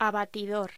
Locución: Abatidor
voz